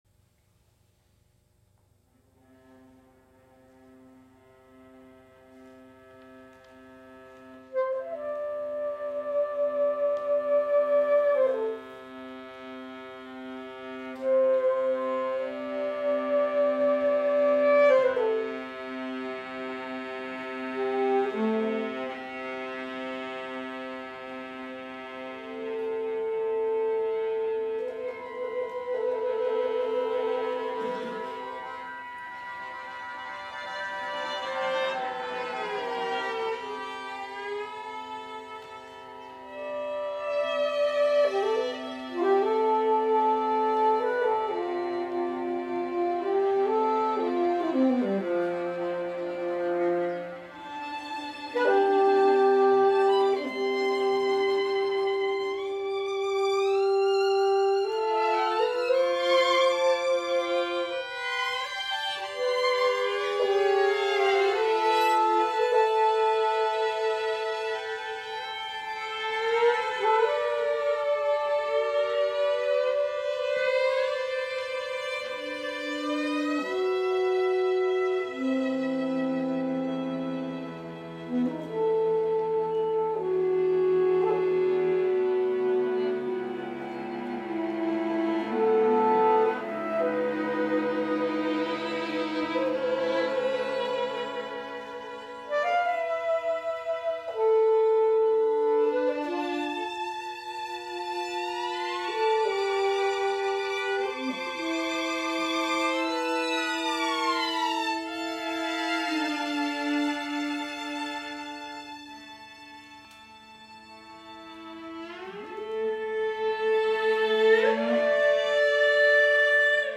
soprano
saxophone
violins
viola
cello